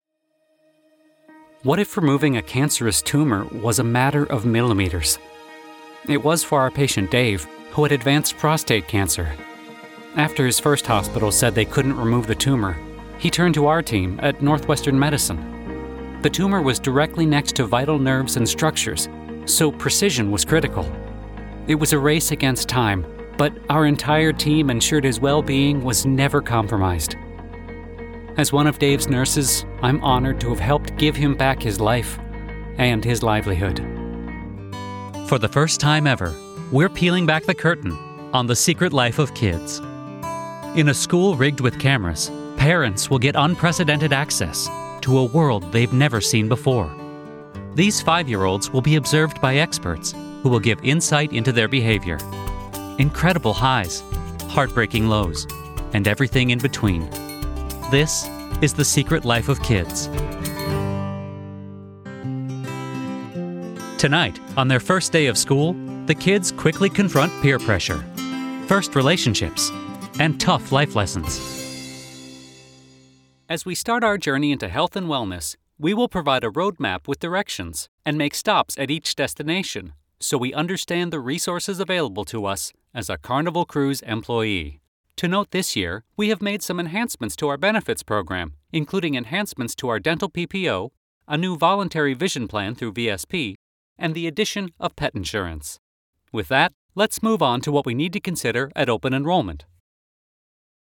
Narration Showreel
Male
American Standard
Confident
Friendly
Reassuring